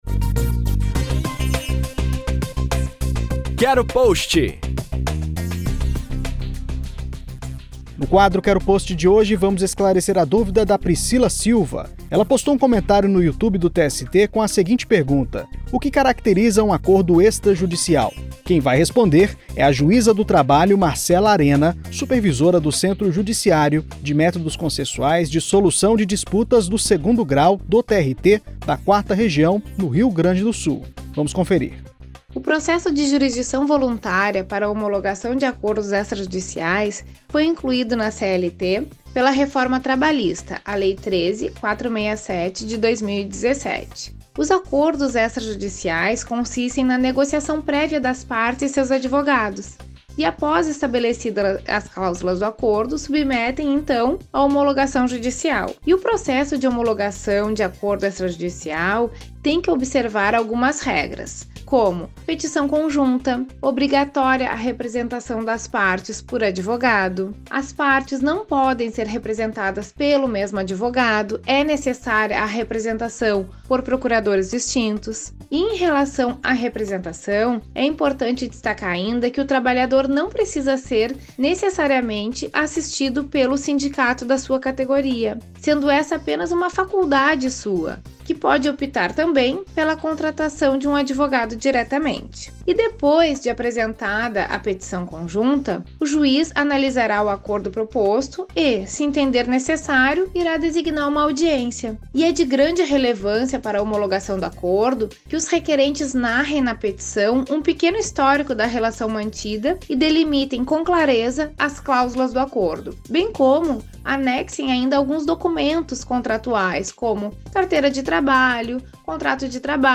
A juíza Marcela Arena, supervisora do Cejusc 2º Grau do TRT-4, respondeu essa pergunta no quadro "Quero Post", da Rádio TST.